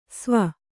♪ sva